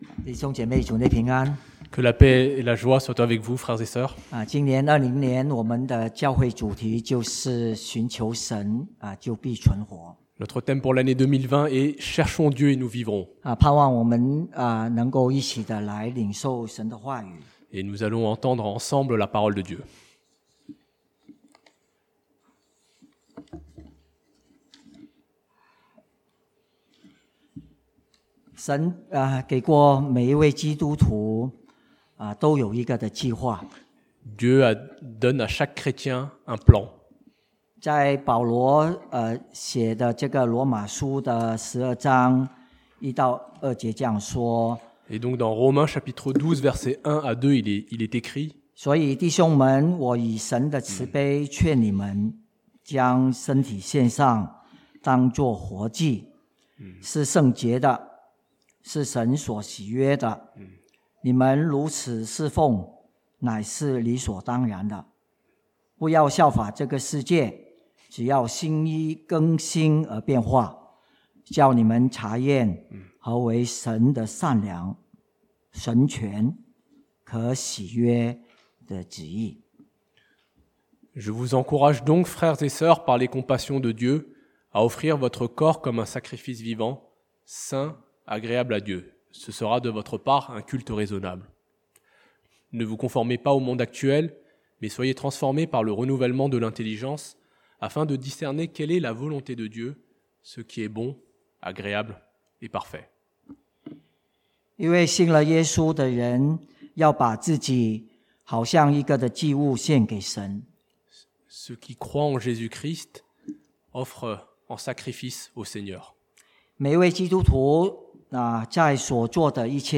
(chinois traduit en français)